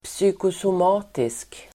Ladda ner uttalet
Uttal: [psykosom'a:tisk]